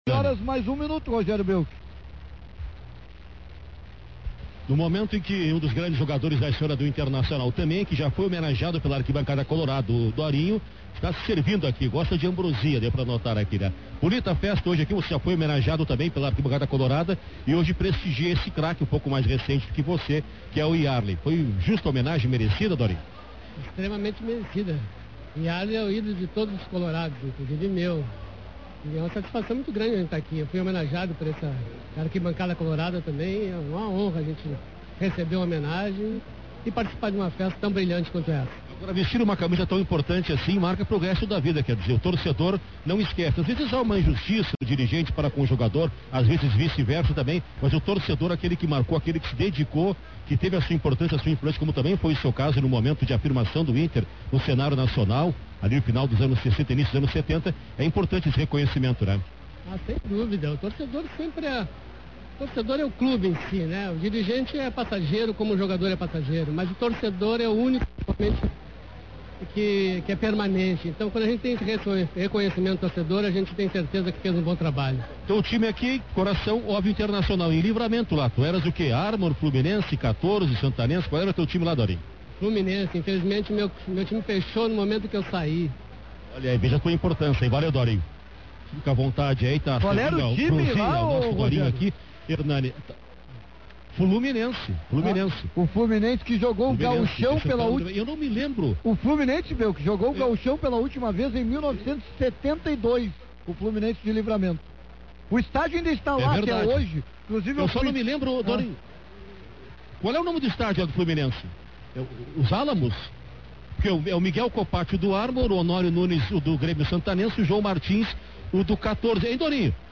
Transmissão Rádio Guaíba Homenagem Iarley – parte 06